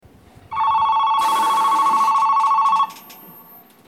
下総松崎駅　Shimosa-Manzaki Station ◆スピーカー：小VOSS
ベルを使用していますが、長く連動することは少ないです。だいたい2,3秒程度で切られることが多いです。
2番線発車ベル
shimosa-manzaki2ban.mp3